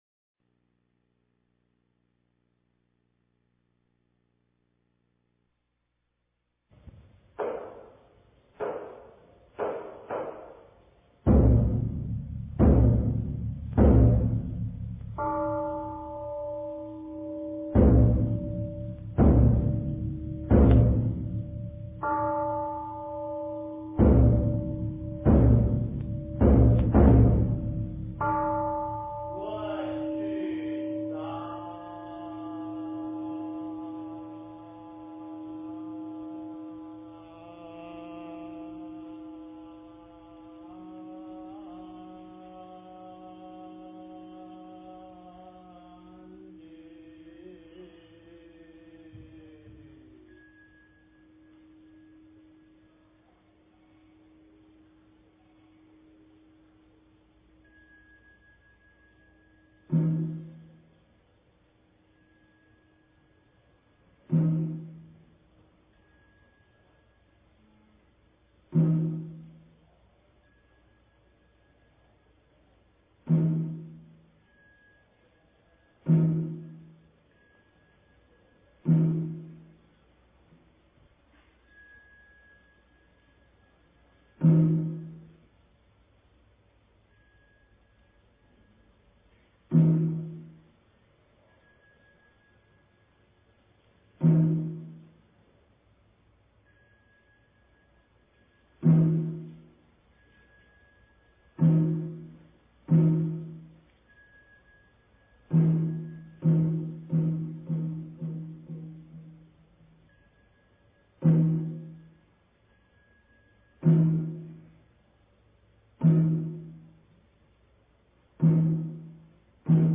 早课--圆光佛学院男众
早课--圆光佛学院男众 经忏 早课--圆光佛学院男众 点我： 标签: 佛音 经忏 佛教音乐 返回列表 上一篇： 早课--福鼎平兴寺 下一篇： 早课--深圳弘法寺 相关文章 大乘金刚般若宝忏法卷上--金光明寺 大乘金刚般若宝忏法卷上--金光明寺...